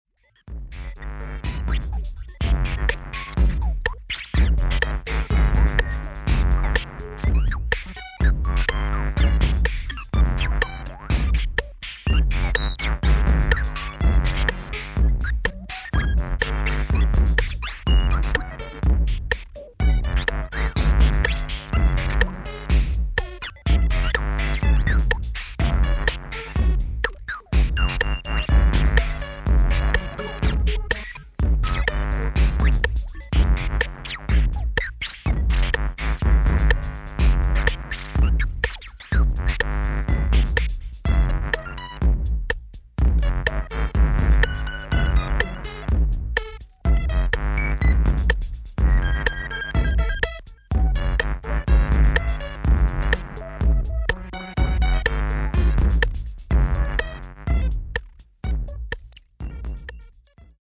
Futuristic deep
electro/techno
Electro Techno Detroit